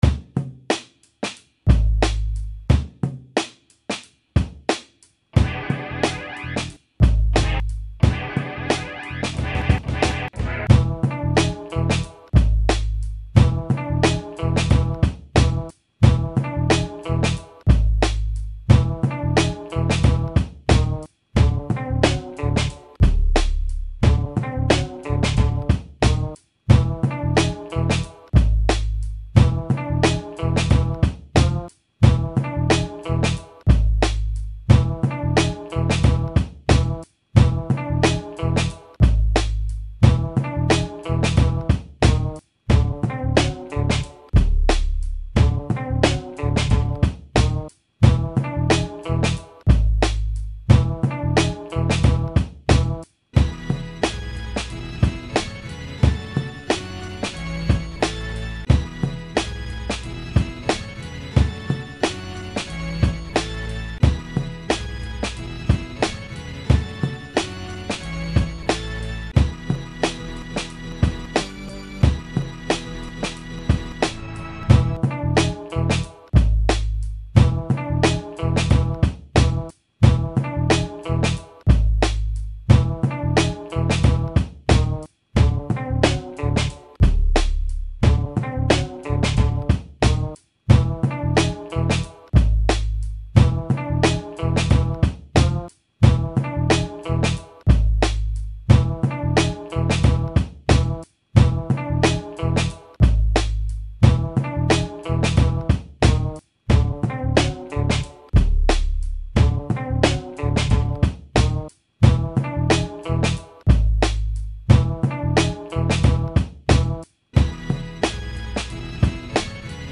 Instrumental - Lyrics